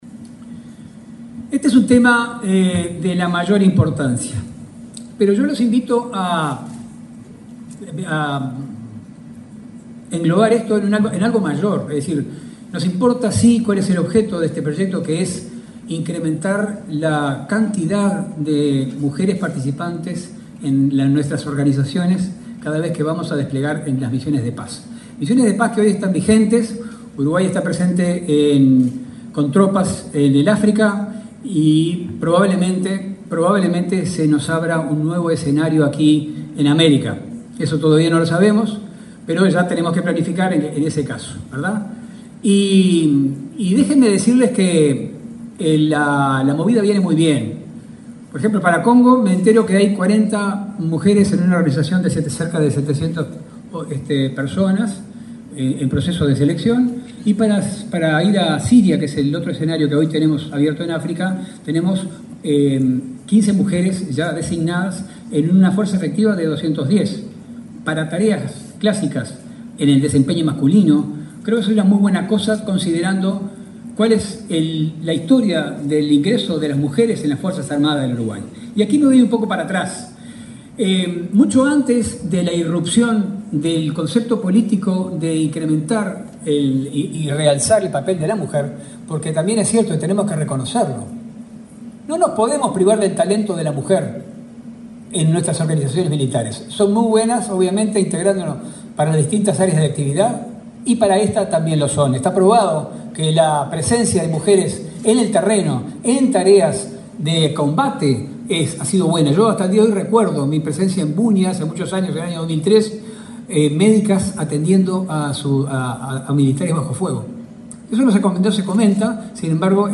Palabras de autoridades en conferencia del proyecto Elsie
El Ministerio de Defensa Nacional; ONU Mujeres y la Agencia Uruguaya de Cooperación internacional (AUCI), en el marco del Proyecto Elsie - Fuerzas Armadas de Uruguay, realizaron una conferencia internacional 2024 cerca de la participación de las mujeres y las operaciones de paz de la ONU. Se expresaron el subsecretario Marcelo Montaner y la gerenta de AUCI, Claudia Romano.